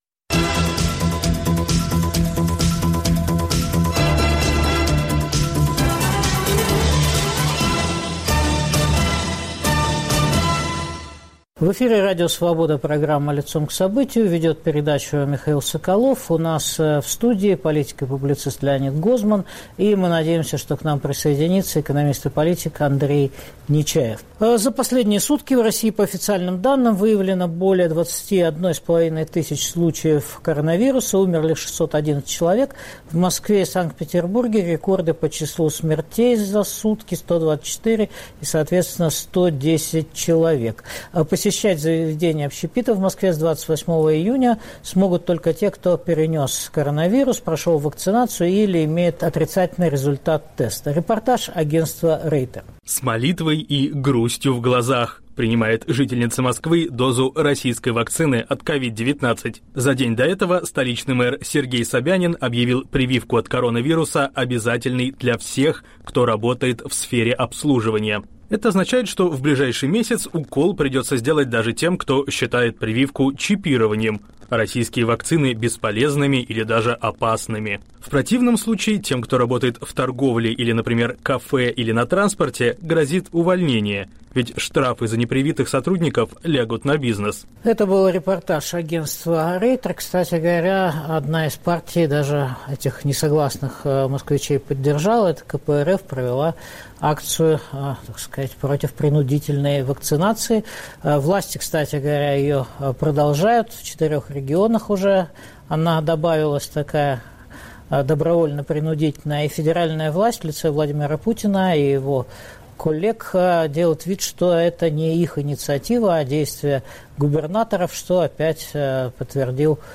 Политический курс режима Путина обсуждаем с политиком Леонидом Гозманом и экономистом Андреем Нечаевым.